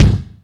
CLUB.wav